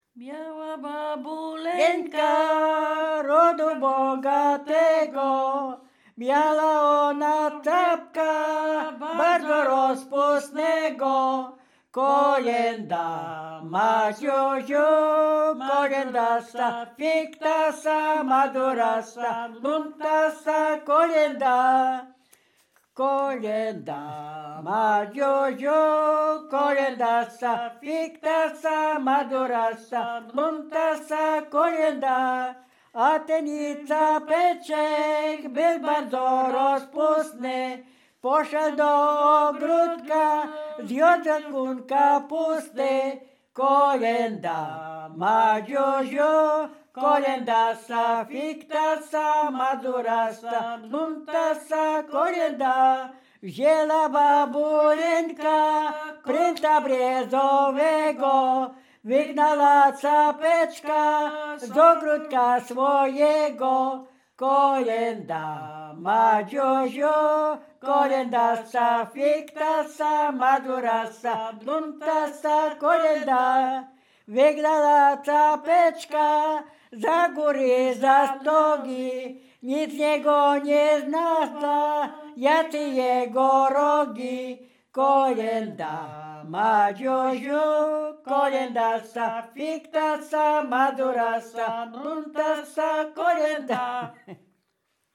Dolny Śląsk
Bukowina Rumuńska
Kolęda